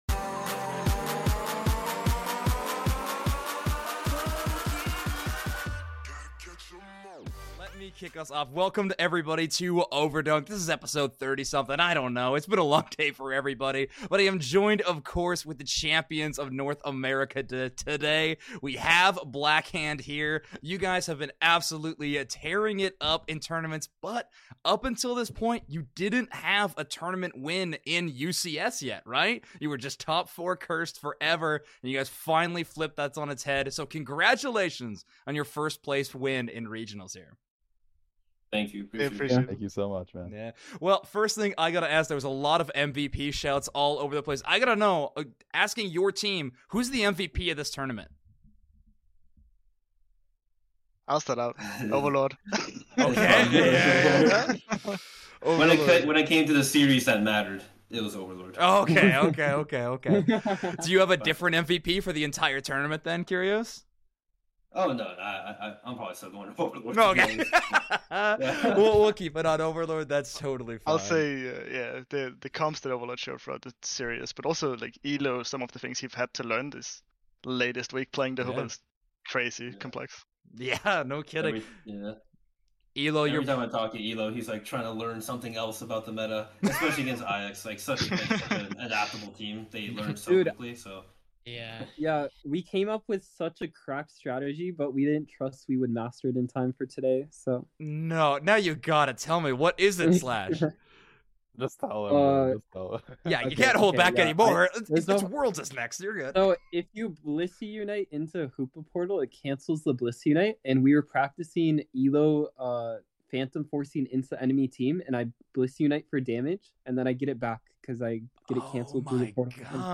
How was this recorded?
This interview was actually recorded about an hour after the Finals of NA regionals ended.